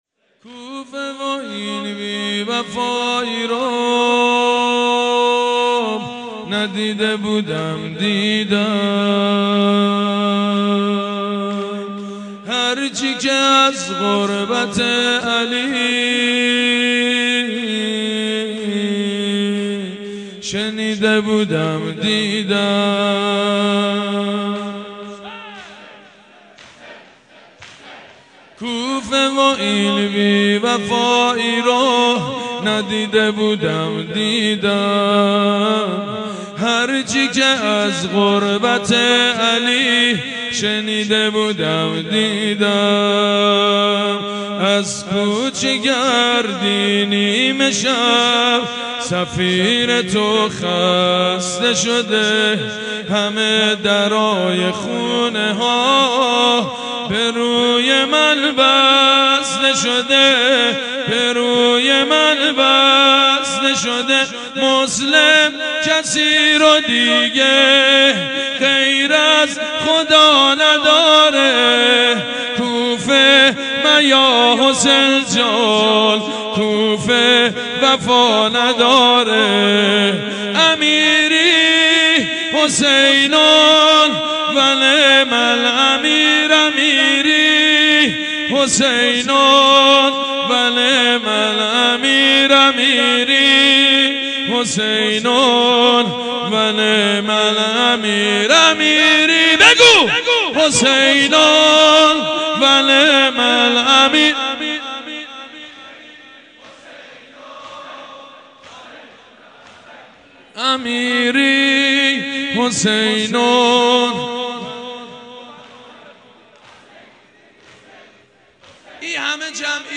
سبک زمینه حضرت مسلم با صدای سید مجید بنی فاطمه